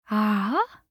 알림음 8_WomanAh5.mp3